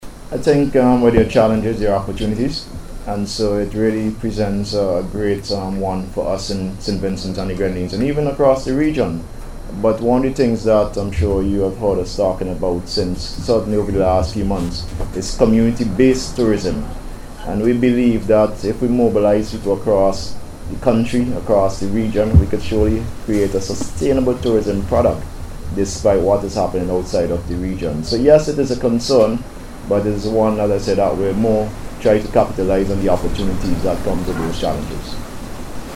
Minister of Tourism, Sustainable Development and Civil Aviation, Dr. Hon Kishore Shallow spoke on the issue, as he addressed a recent media event, held to provide an update on Sailing Week.